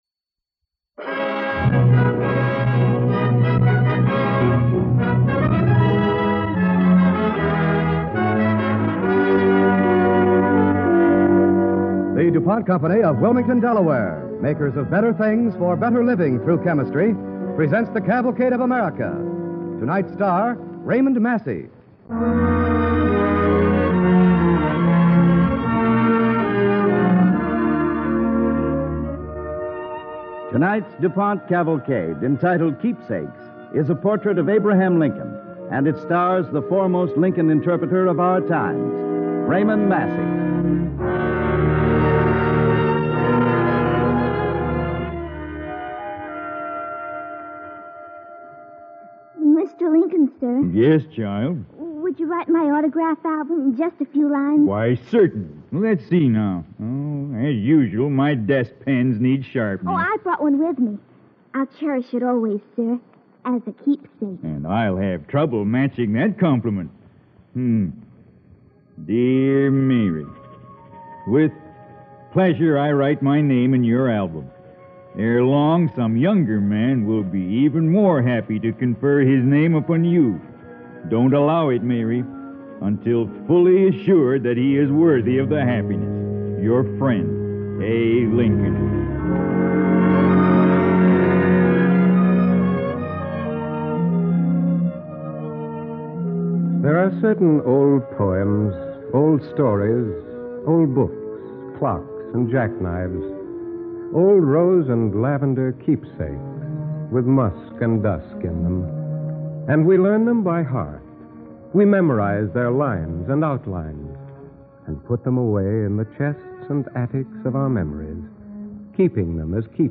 starring Raymond Massey